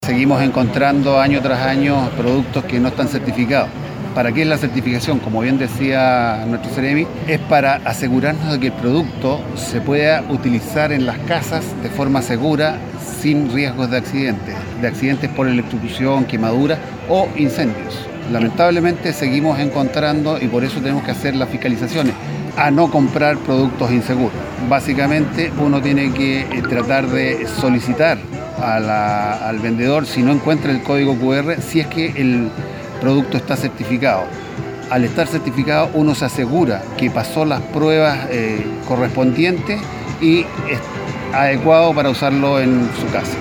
Desde la Superintendencia de Electricidad y Combustibles reiteró que además los establecimientos comerciales deben contar con productos que cumplan con la normativa y esto debe ser exigido por los usuarios.
05-DIRECTOR-SEC.mp3